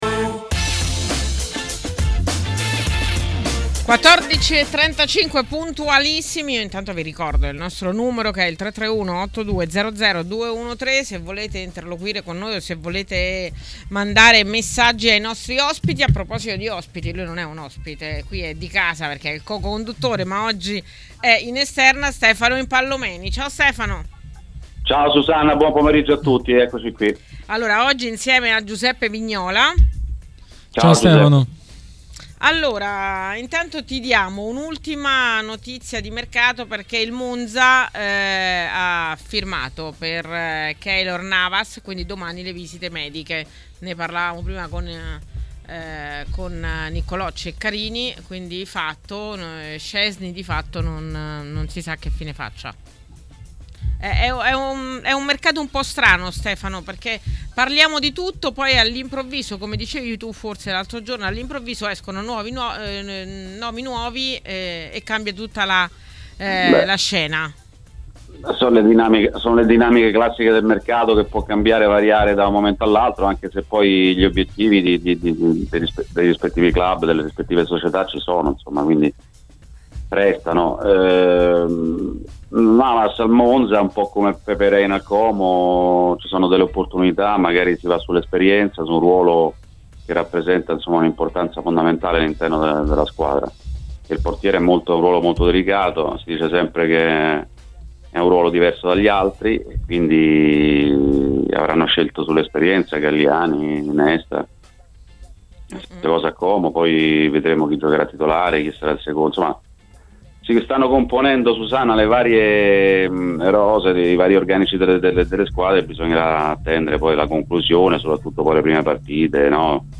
Ospite di Calciomercato e Ritiri, trasmissione di TMW Radio, è stato mister Gigi Maifredi.